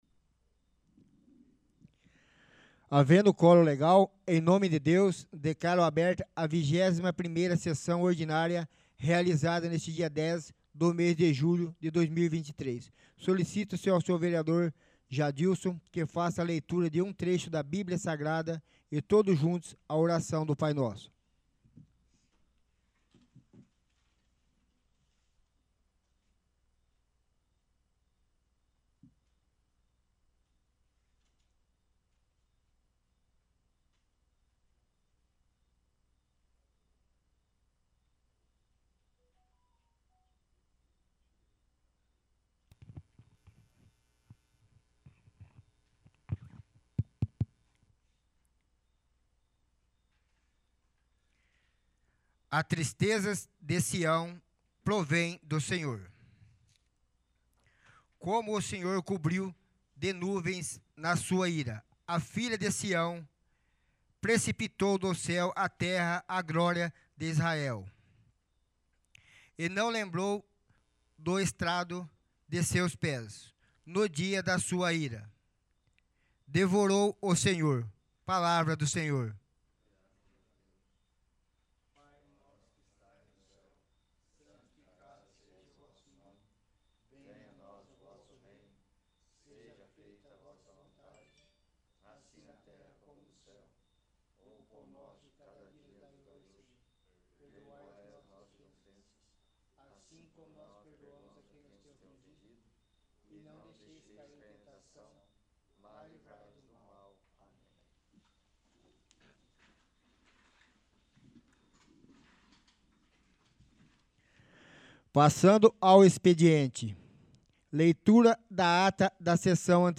21º. Sessão Ordinária